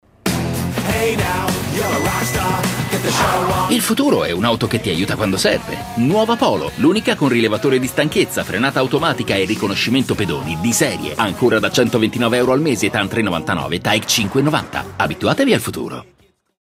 SPOT TV